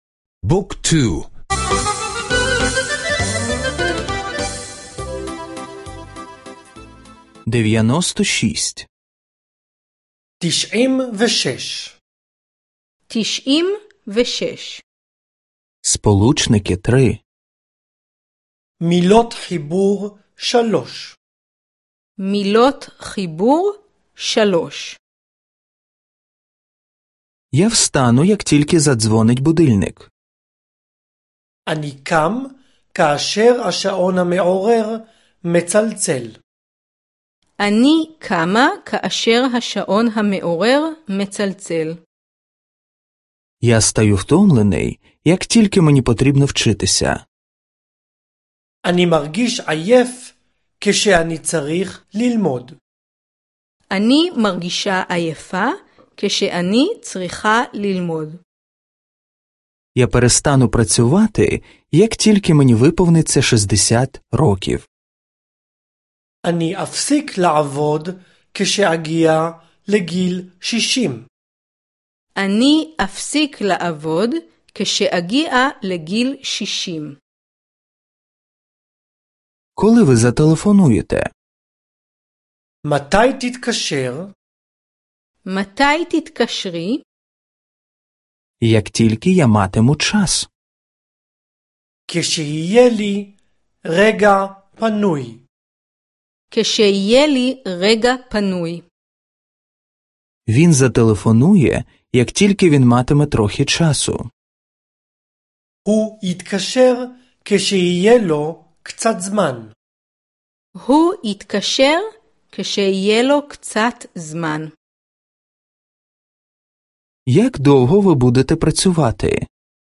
Аудіо уроки мови івриту — завантажити безкоштовно